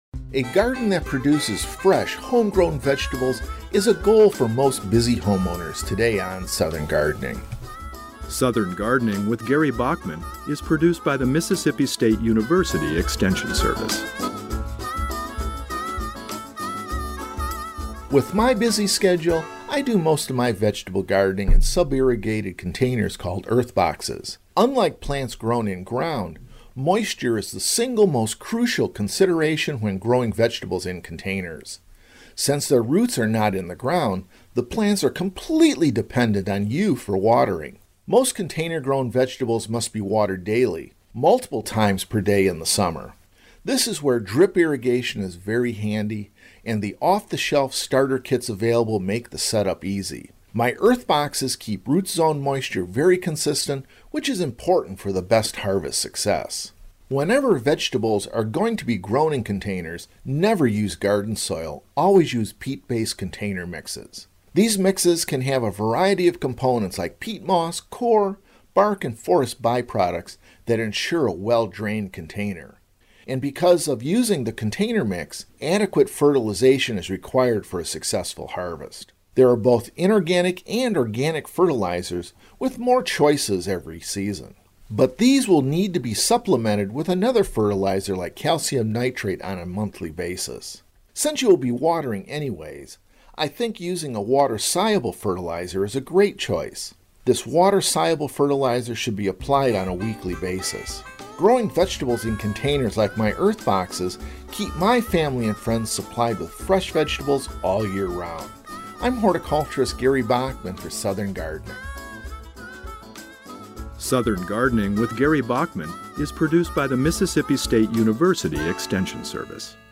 Ornamental Horticulture Specialist